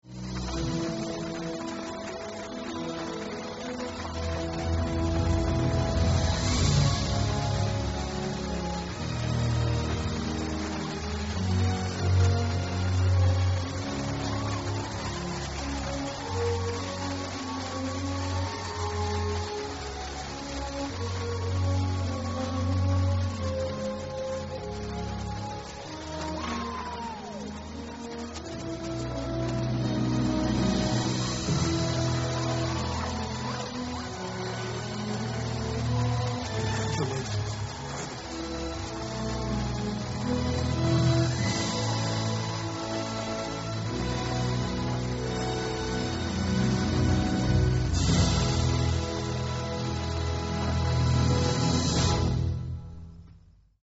Levy: Big orchestral out.
John Williamsesque arrangement